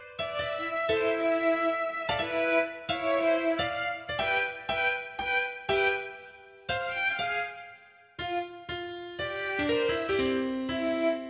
Продукт уже можно генерировать, но сейчас его качество сложно назвать приемлемым, сеть показывает лишь базовое понимание гармонических сочетаний и ритма.